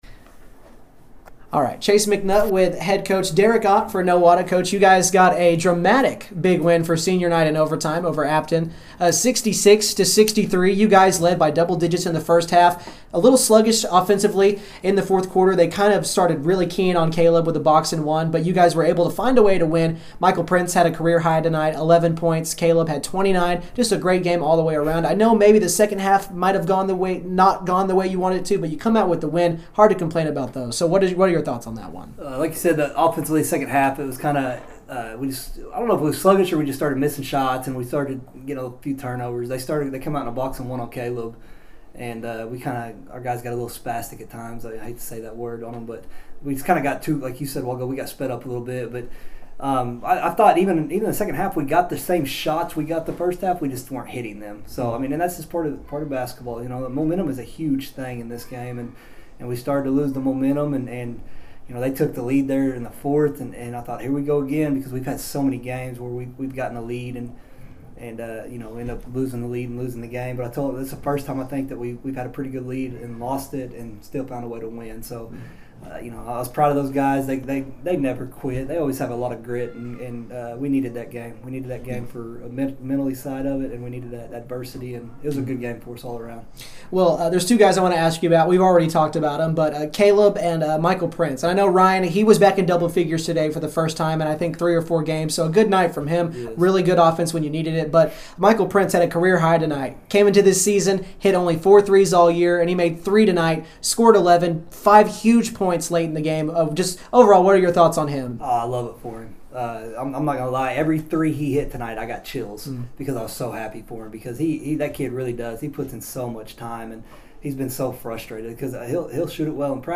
interview after the game.